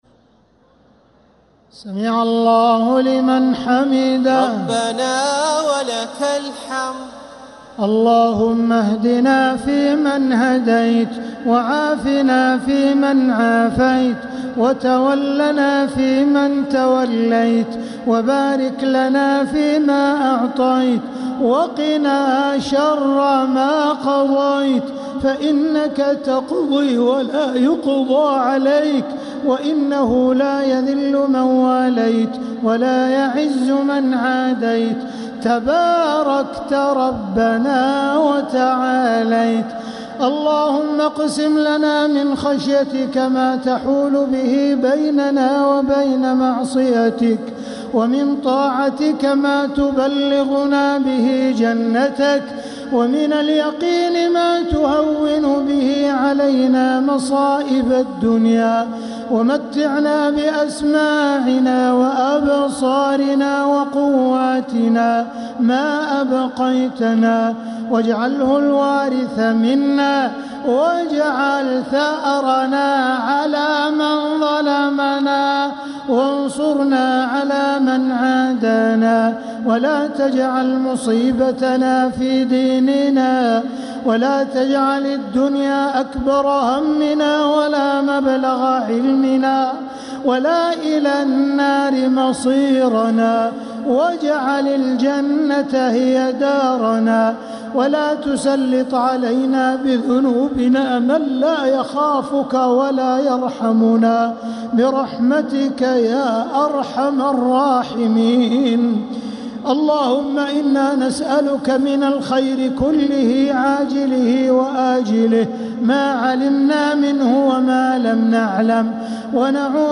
دعاء القنوت ليلة 10 رمضان 1447هـ | Dua 10th night Ramadan 1447H > تراويح الحرم المكي عام 1447 🕋 > التراويح - تلاوات الحرمين